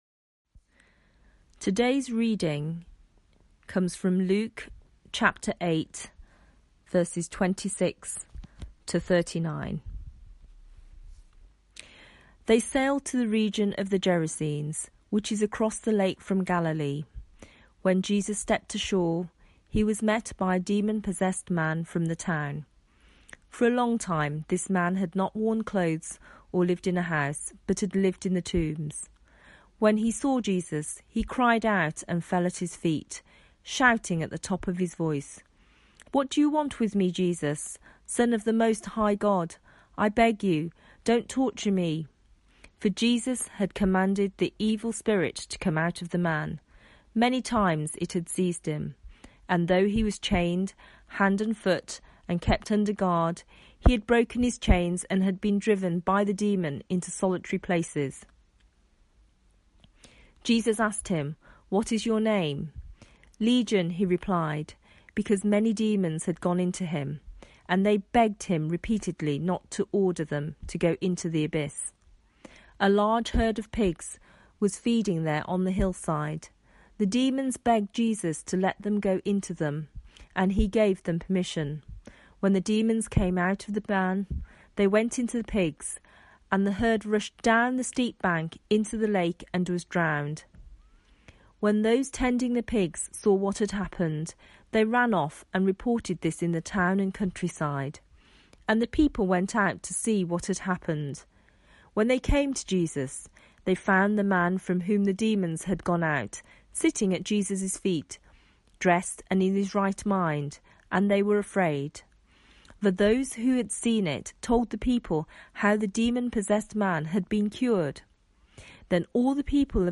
Passage: Luke 8:26-39 Series: Jesus at work with ... Theme: ...a demon possessed man Online Service Talk (Audio) Search the media library There are recordings here going back several years.